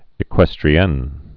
(ĭ-kwĕstrē-ĕn)